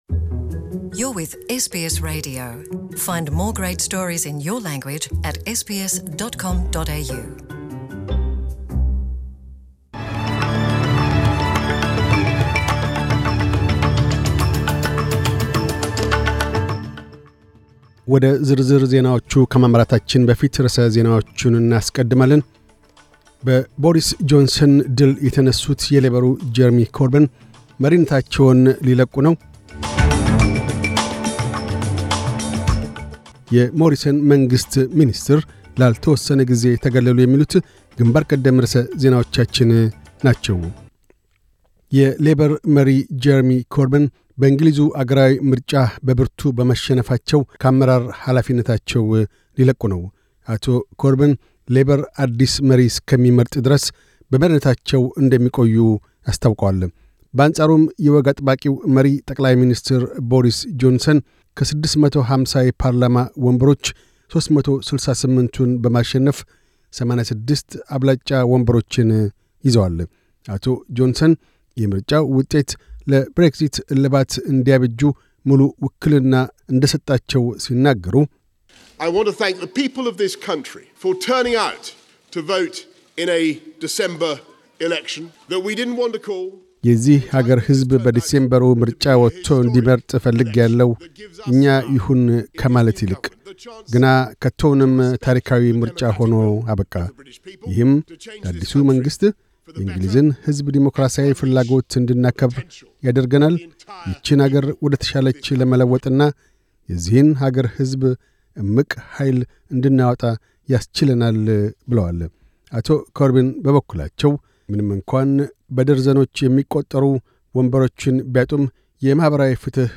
News Bulletin 1312